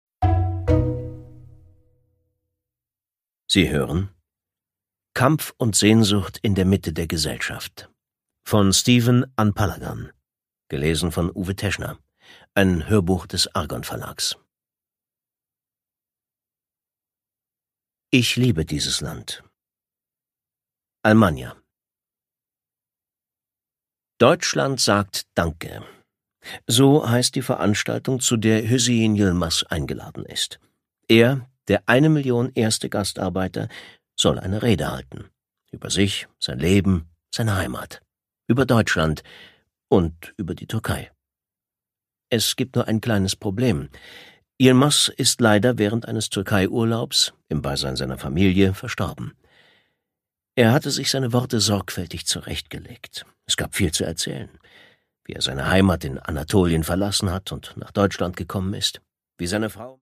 Produkttyp: Hörbuch-Download